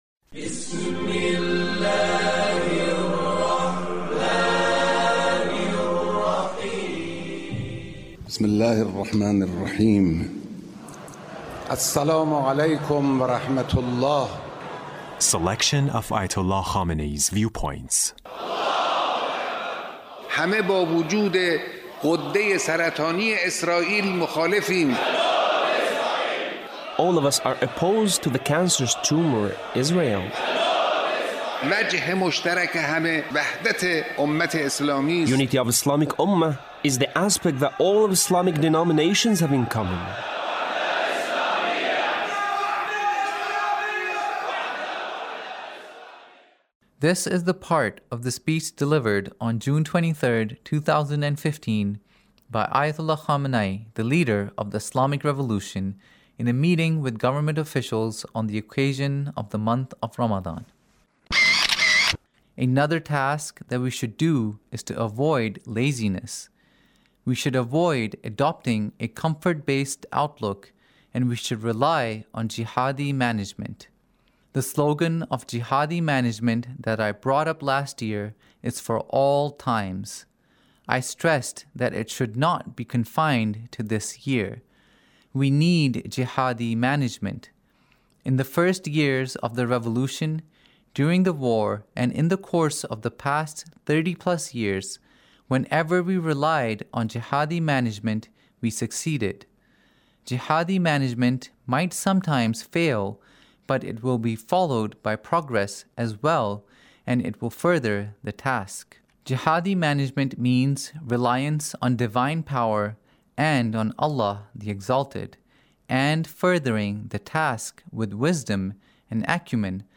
Leader's Speech On The Month of Ramadhan in a Meeting with the Government Officials